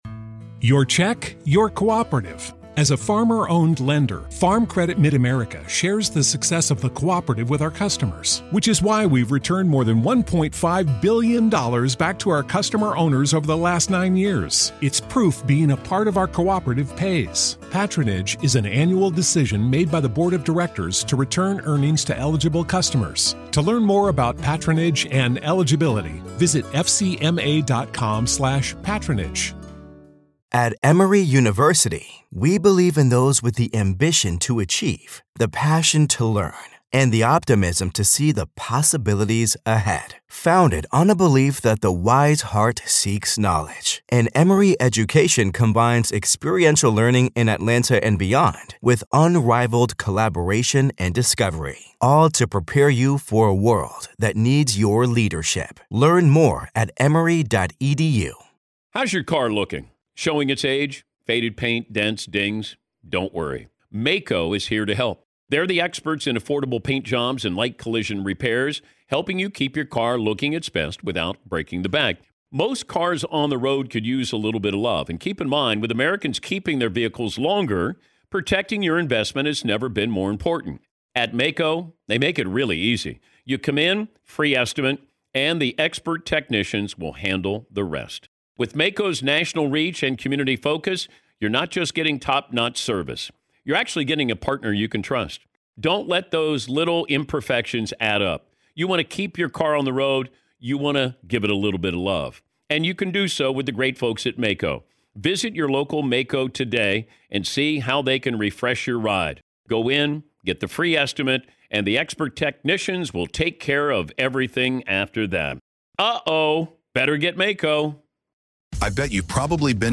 If you enjoy our interviews and conversations about "The Dead," why not listen ad-free?